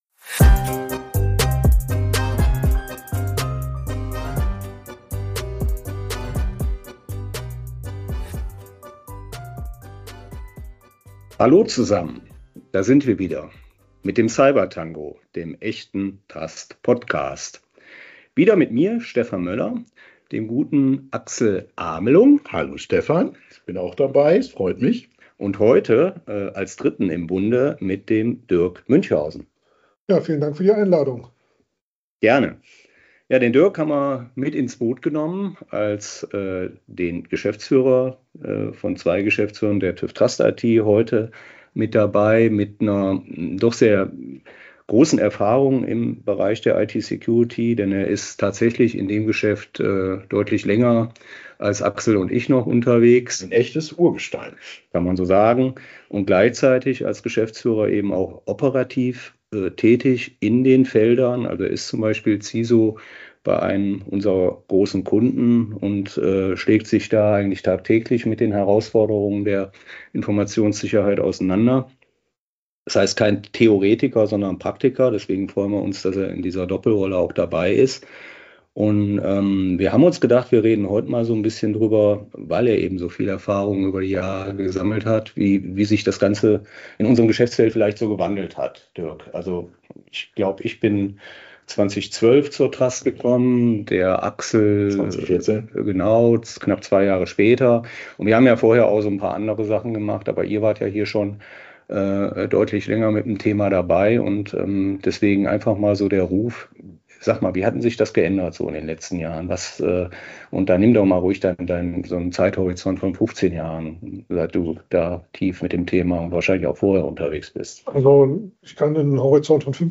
Doppelte Länge, doppelter Gesprächsstoff: